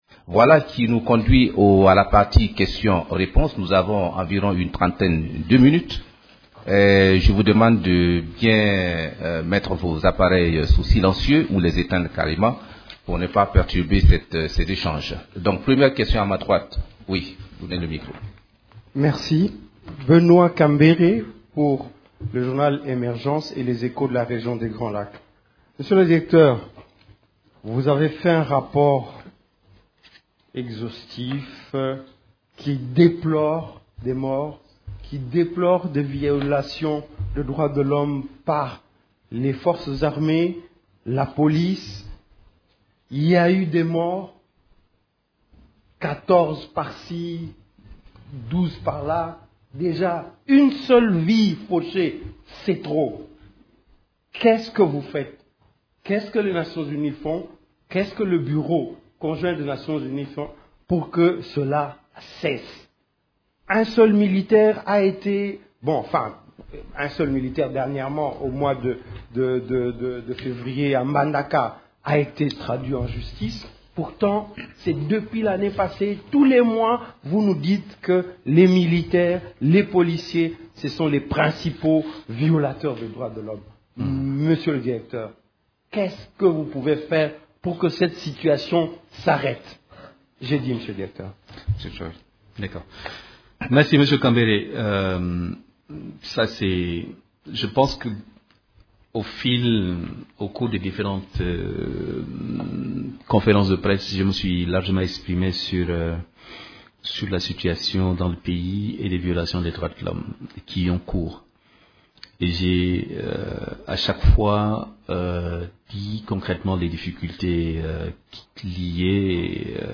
Le Bureau conjoint des Nations unies aux droits de l’homme (BCNUDH) a tenu vendredi 23 mars une conférence de presse sur le rapport thématique, intitule ‘’ Recours illégal et disproportionné à la force lors de manifestations publiques en RDC janvier 2017-janvier 2018’’. A cette occasion, le Directeur du BCNUDH, Abdoul Aziz Thioye, a fait allusion à la mesure du gouvernement portant sur la levée d’interdiction des manifestations publiques dans ce pays, que la ministre congolaise des Droits humains a annoncé au cours de la 37e session du Conseil des Droits de l’homme à Genève.